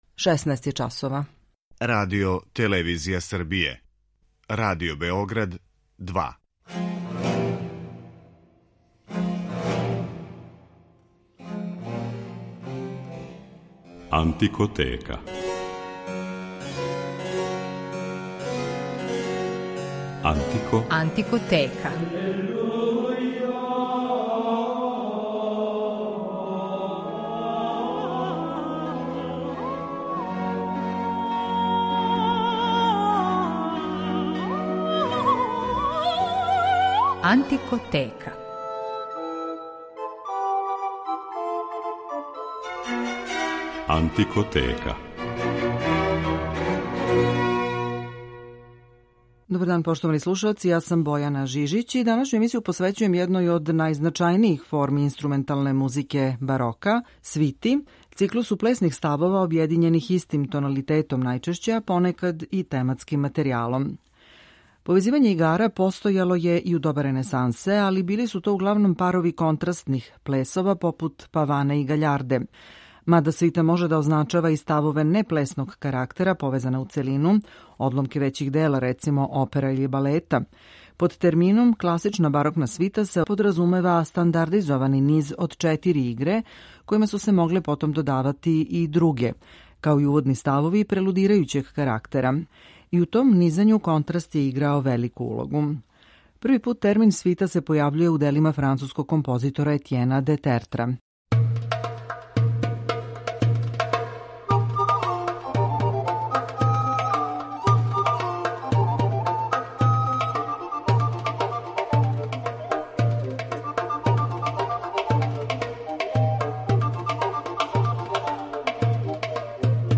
Управо у свити су неки од највећих мајстора барока досегли врхунце у инструменталном стварању, попут Луја Купрена, Жан-Батисте Лилија, Арканђела Корелија, Георга Филипа Телемана или Јохана Себастијана Баха, чије ћете композиције моћи да слушате у данашњој емисији. У рубрици Антикоскоп представљамо појединачне игре у основном обрасцу свитног облика: алеманду, сарабанду, куранту и жигу.